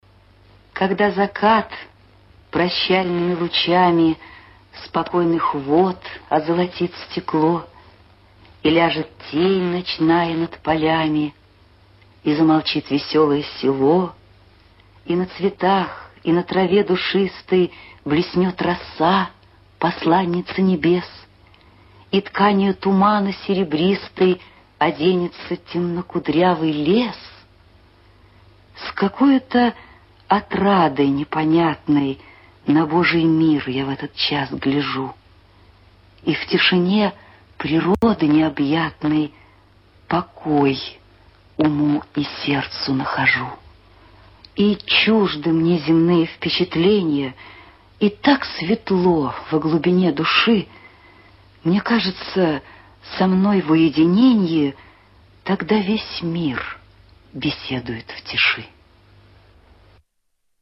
Прослушивание аудиозаписи стихотворения с сайта «Старое радио»